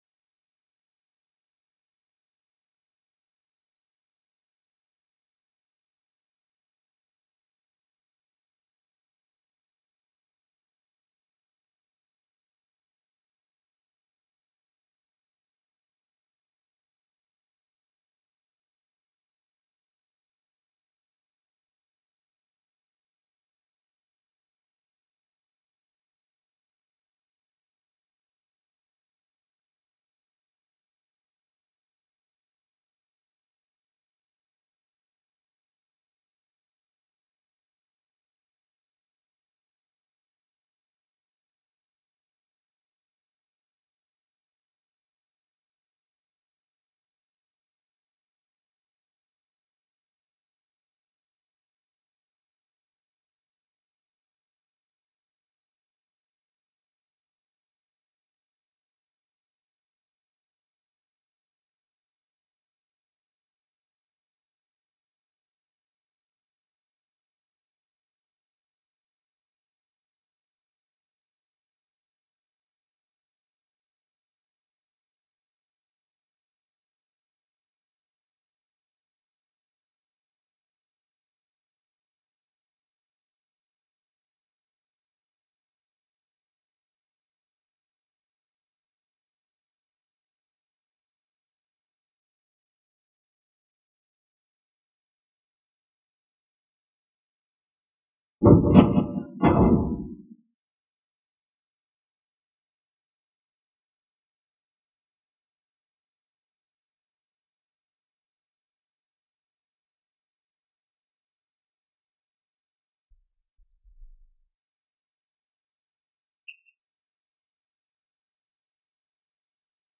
062 After zuhar Namaz elan 26 September 2021 (18 Safar 1443HJ) Saturday]
After Namaz Bayan